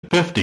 50 Boeing GPWS
A GPWS Callout which informs you how high you are.
50-boeing-gpws.mp3